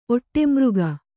♪ oṭṭemřga